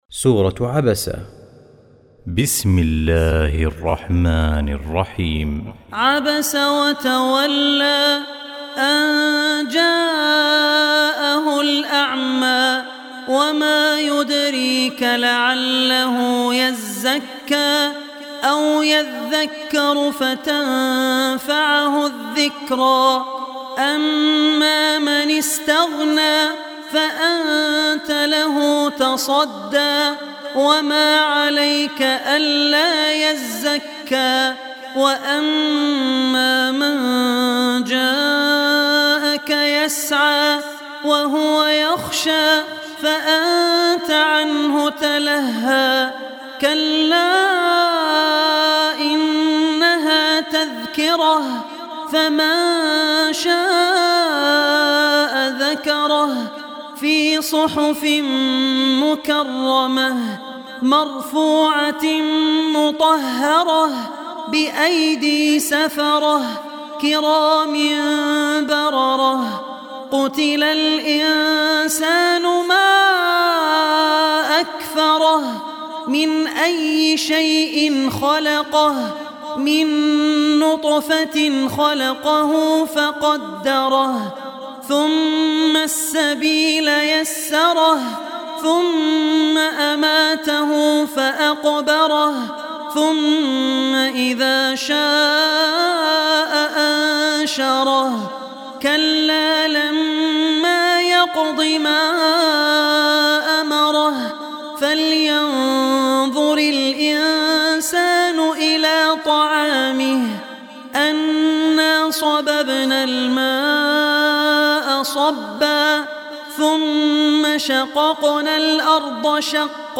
Surah Abasa Recitation
80-surah-abasa.mp3